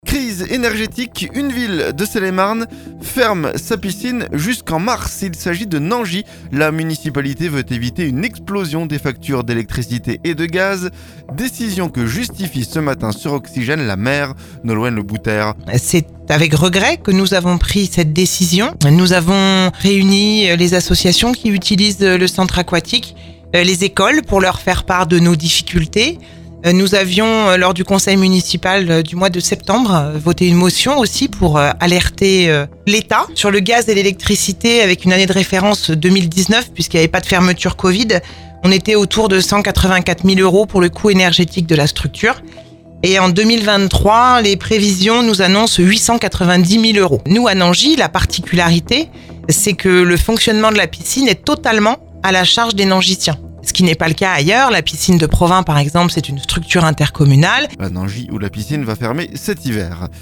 NANGIS - La maire explique la fermeture de la piscine pour l'hiver
La municipalité veut éviter une explosion des factures d'électricité et de gaz...Décision que justifie ce lundi sur Oxygène la maire Nolwenn Le Bouter.